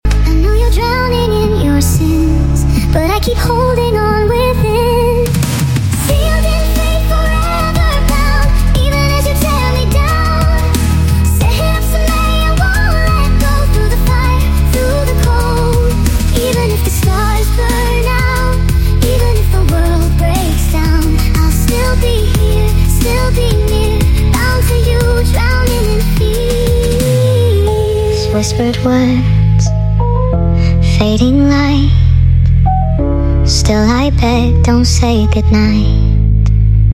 Music Generated by Suno AI